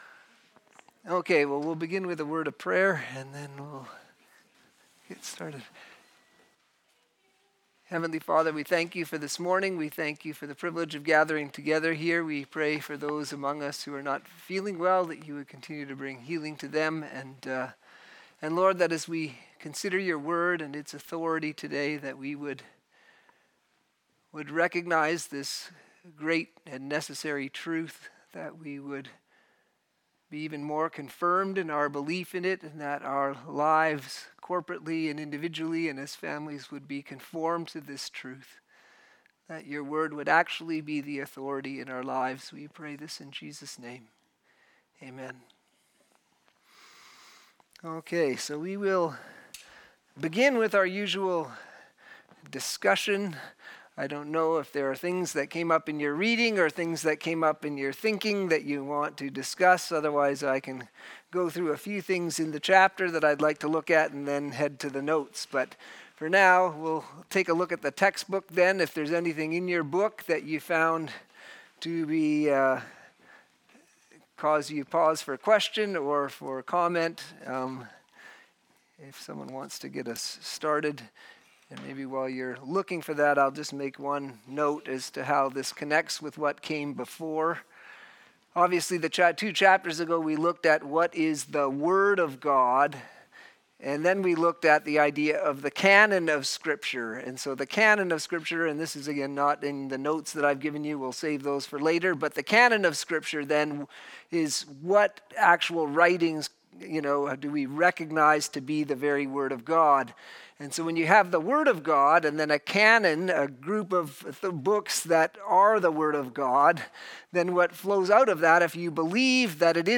Systematic Theology Class - Teaching